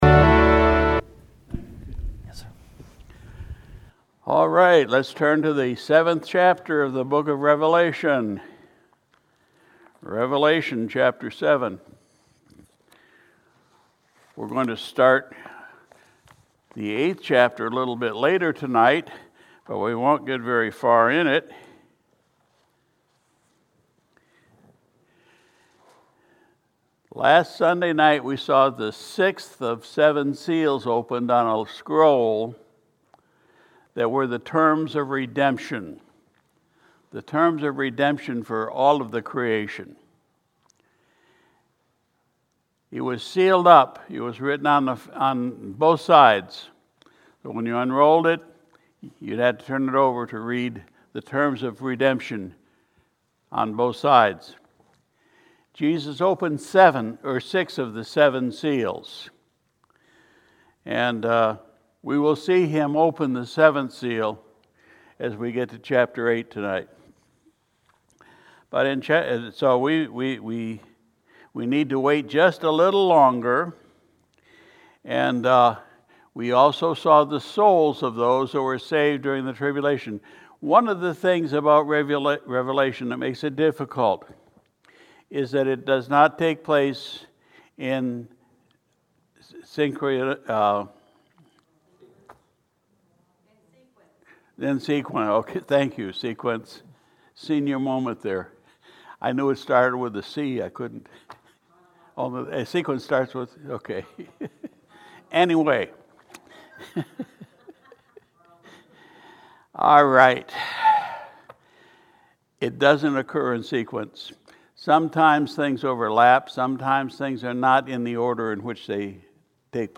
February 6, 2022 Sunday Evening Prayer Service We continued our study in the Book of Revelation (Revelation 7:1-8:13)